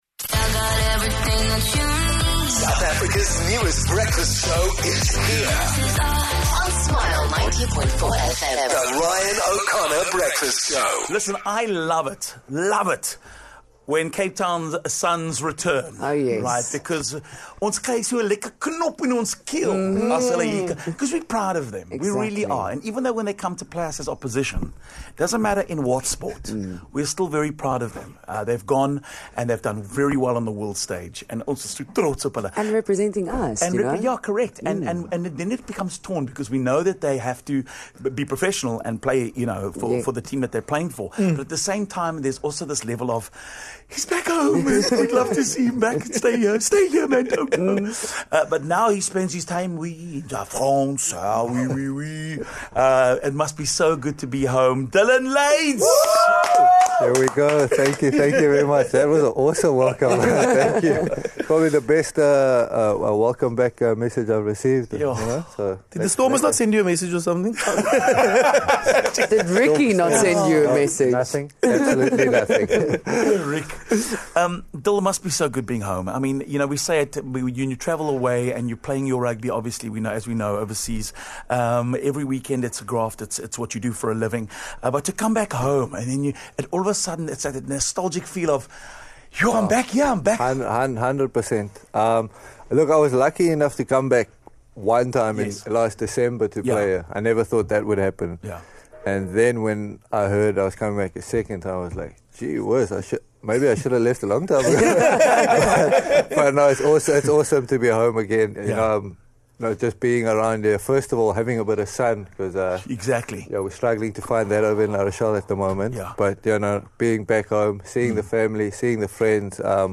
He joined us in studio to talk about what it's like returning to Cape Town to play against some of his former teammates.